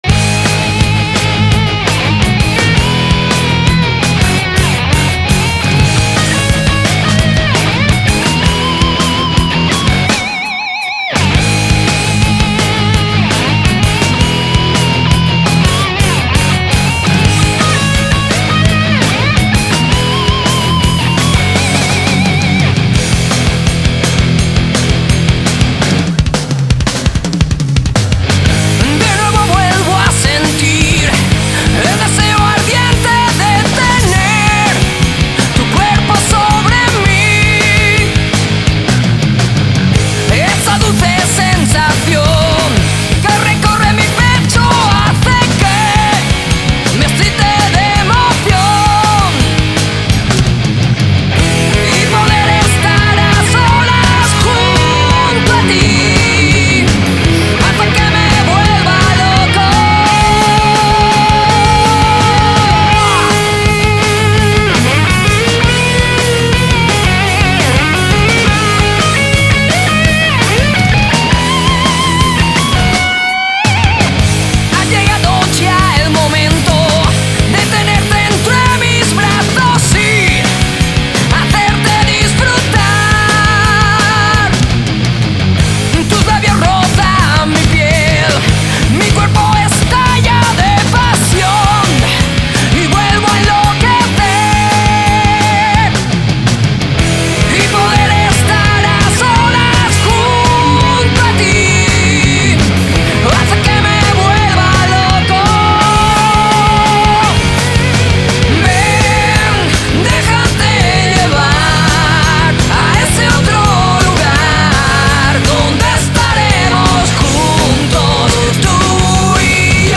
Guitarra Solista
Bajo
Batería